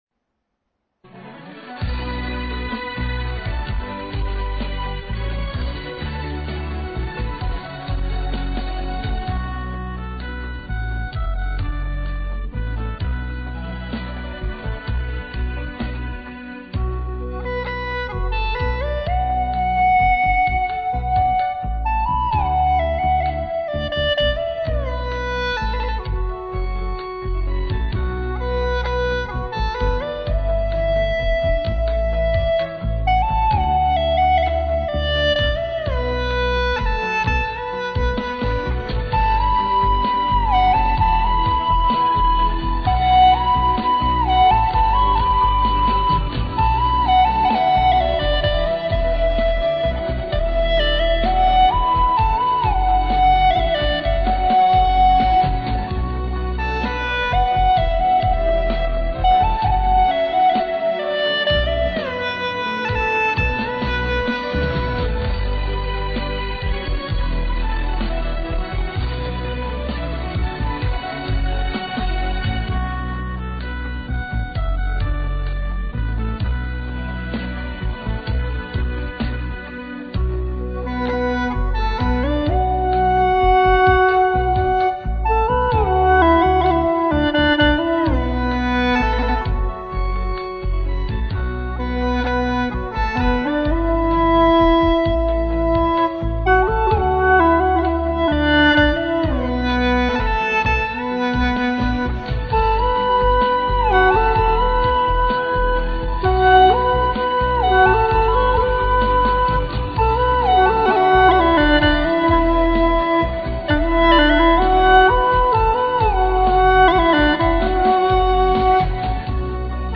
调式 : D 曲类 : 民族
【大小D调】